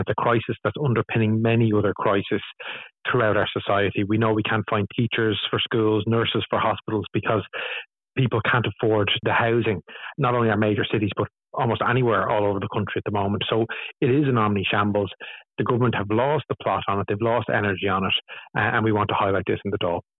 The party’s Deputy Duncan Smith says the government’s ‘lost the plot’ on housing: